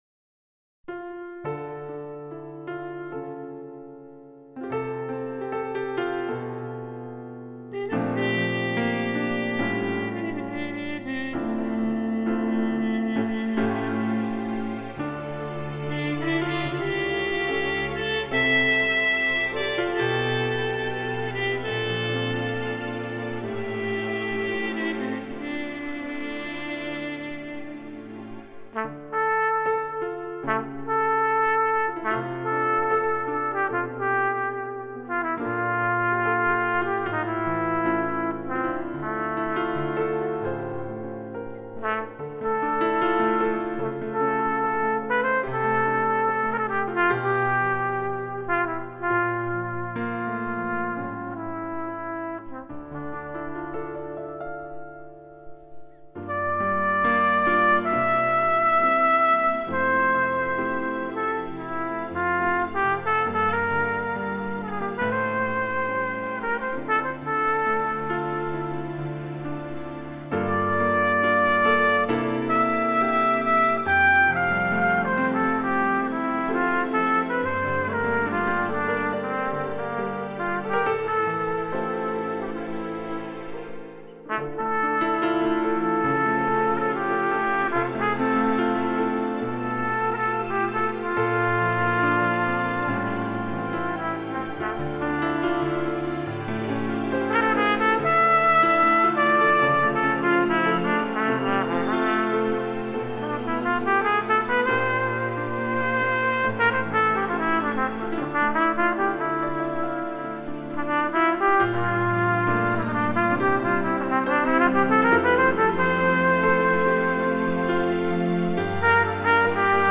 Due trombe due generazioni
in multiplay recording system
Non ci siamo preoccupati di correggere qualche imperfezione (dovuta alla registrazione dal vivo) proprio perchè il "ricordo" doveva appartenere a me e a mio figlio.